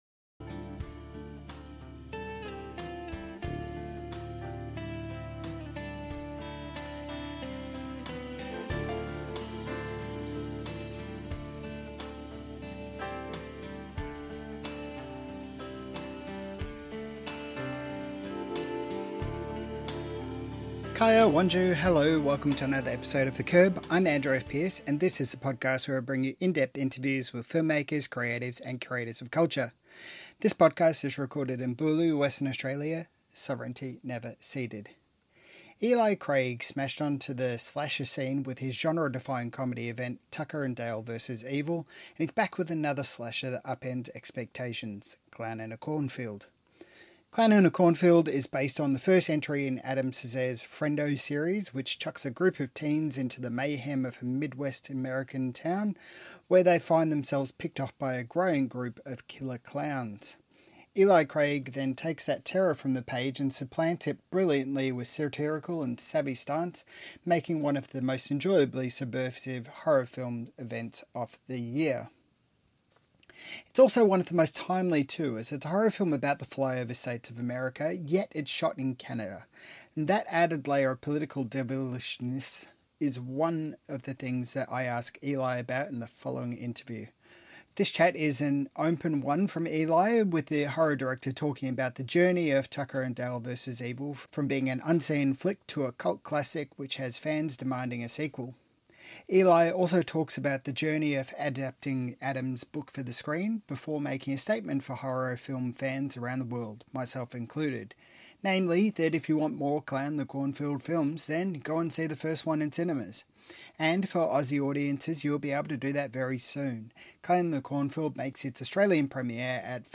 That added layer of political devilishness is one of the things that I ask Eli about in the following interview.